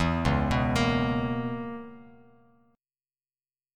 Dbm13 chord